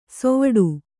♪ sovaḍu